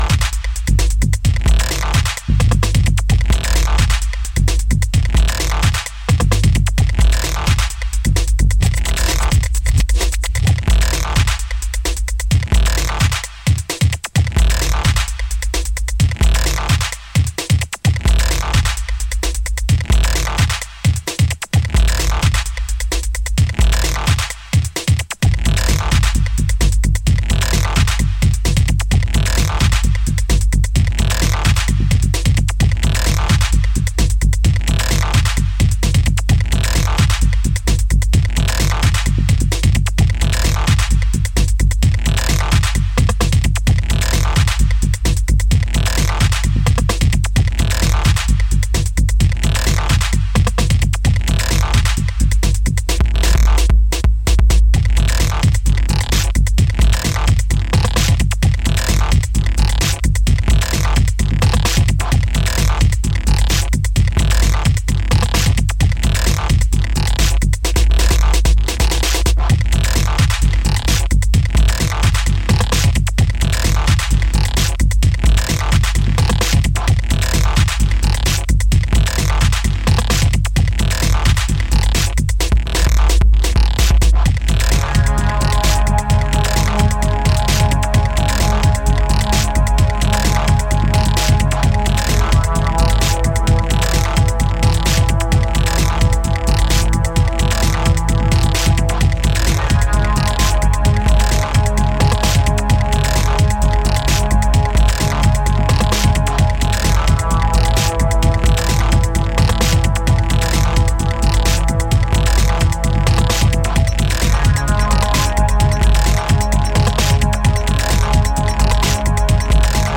a remastered version